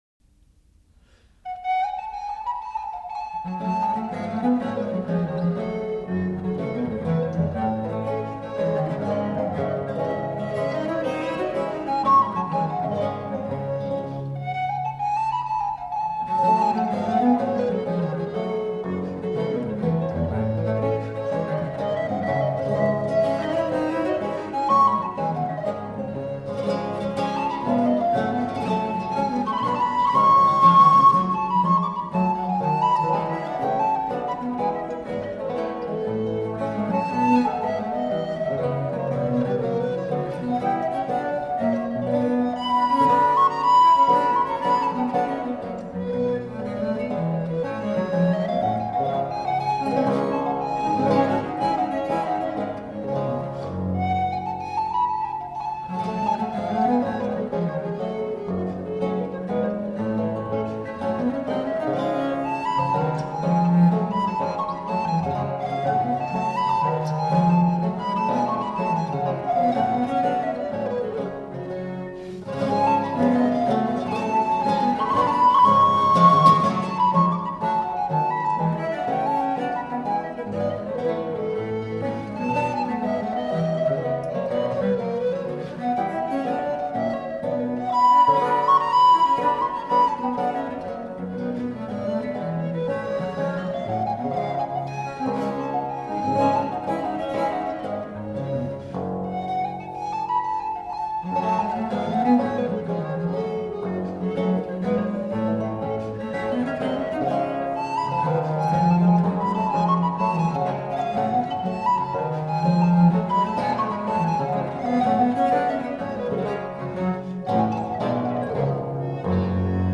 Reflective, historically-informed performance on the lute.
Recorder, Theorbo, Viola da Gamba